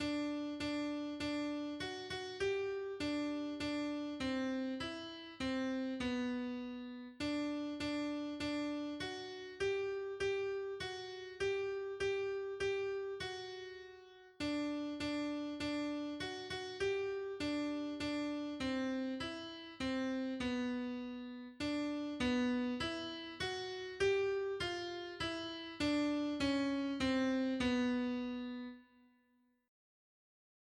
Para aprender la melodía os dejo estos MIDIS, con la voz principal destacada por encima del resto.
en-la-mas-fria-noche-midi-a.mp3